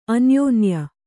♪ anyōnya